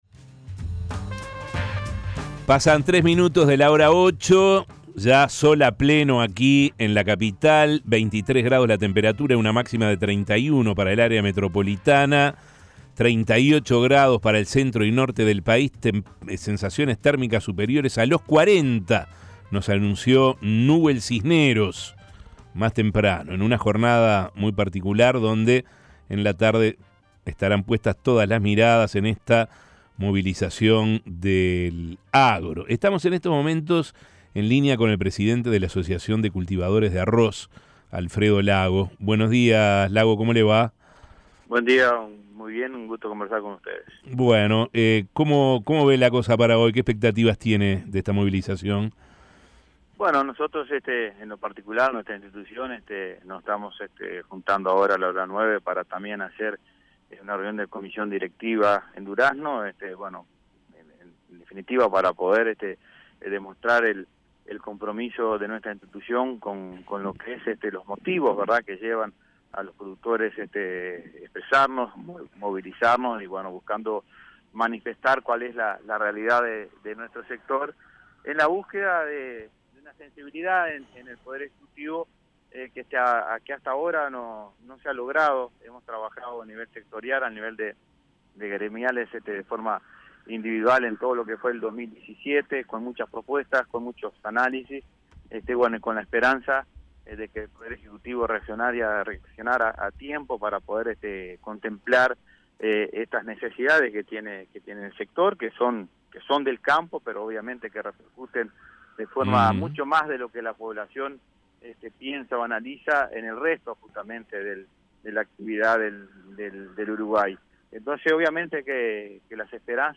Escuche la entrevista de La Mañana: